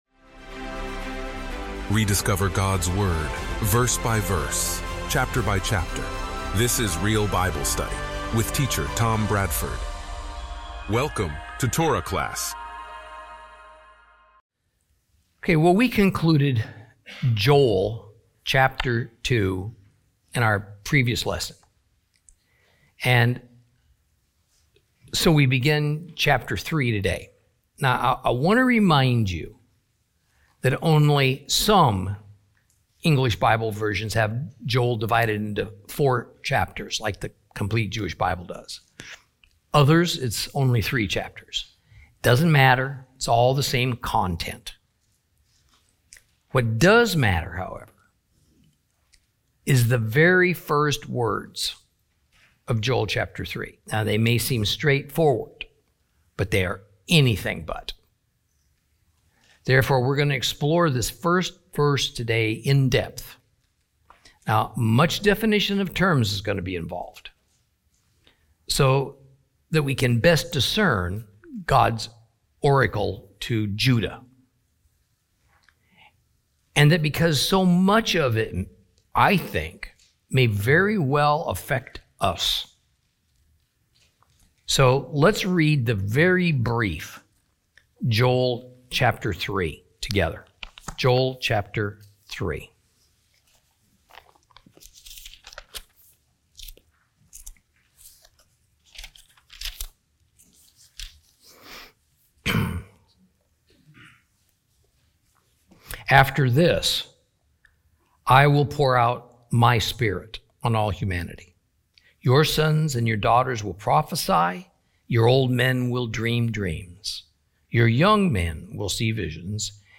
Teaching from the book of Joel, Lesson 6 Chapter 3.